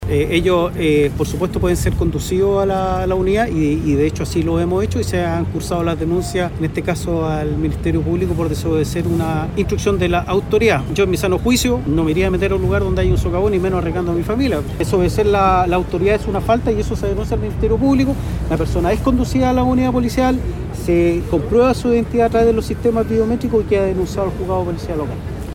Al respecto, el jefe de la V Zona de Carabineros, general Edgard Jofré, mencionó que por esta conducta se han cursado denuncias al Ministerio Público por desobedecer a la autoridad.